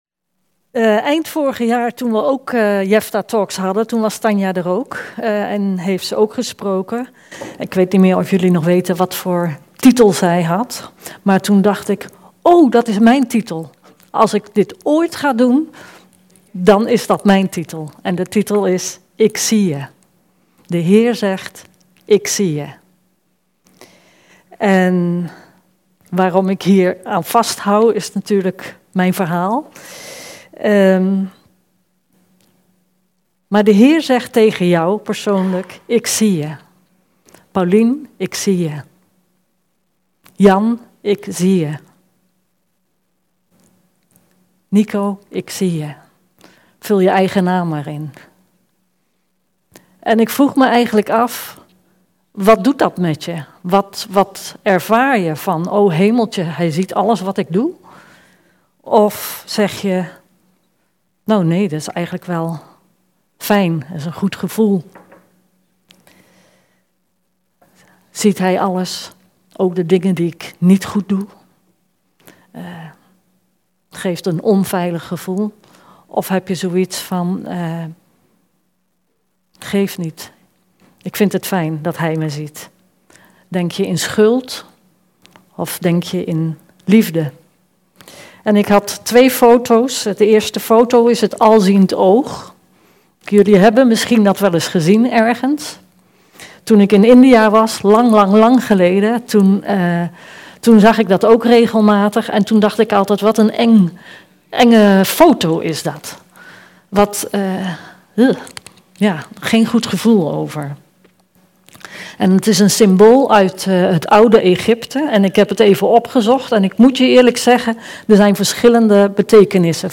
Preken – Evangelische Kerk Jefta